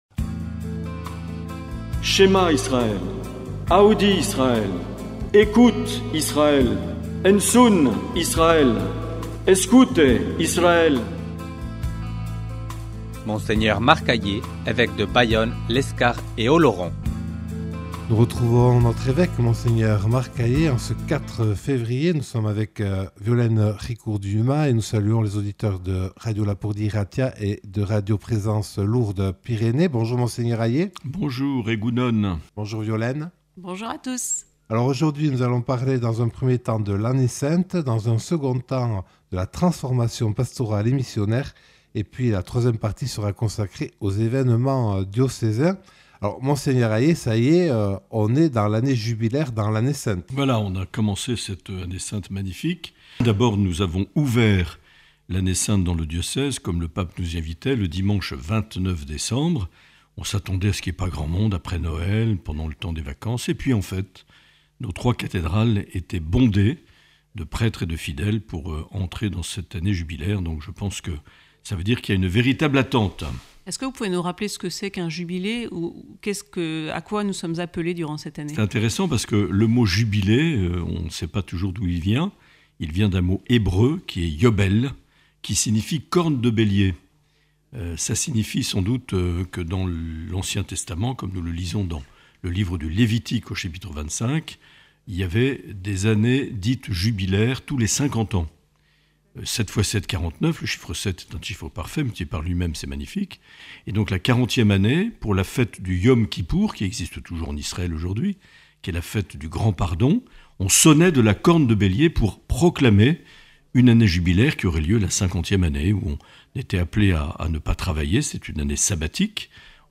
Dans cet entretien enregistré le 4 février 2025, Mgr Marc Aillet répond aux questions de Radio Lapurdi et de Radio Présence Lourdes Pyrénées.